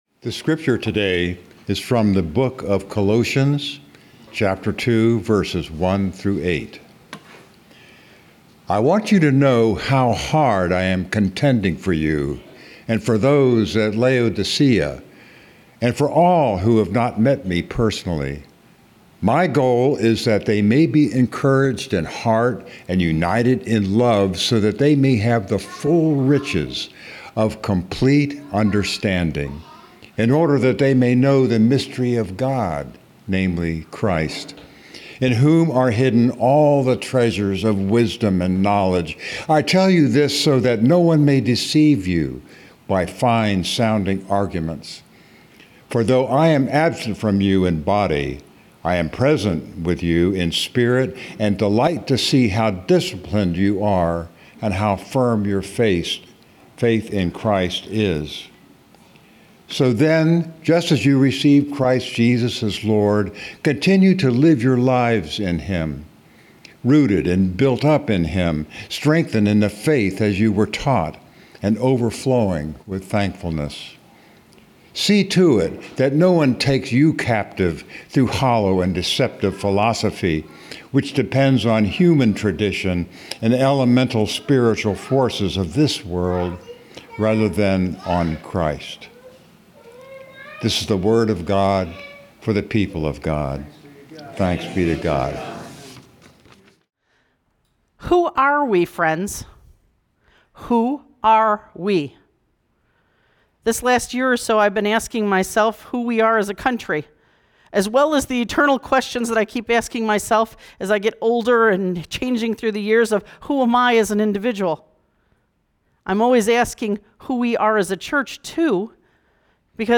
September 14, 2025 Sermon Audio